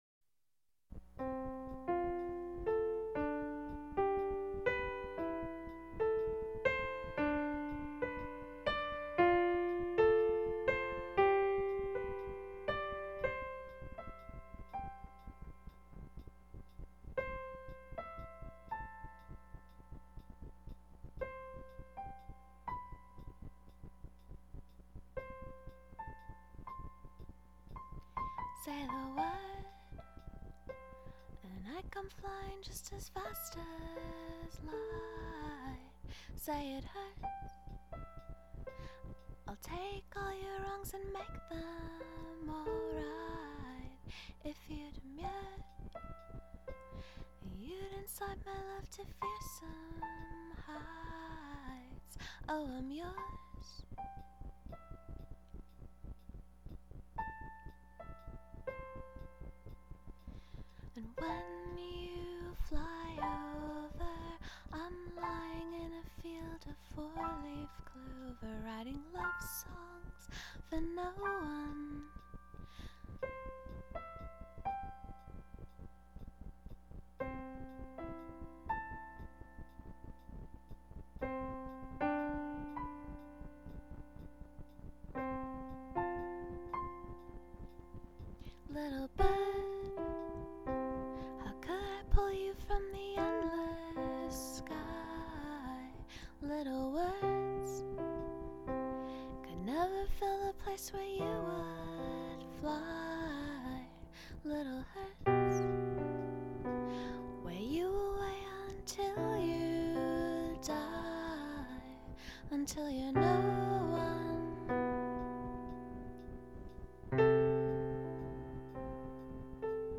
quiet demo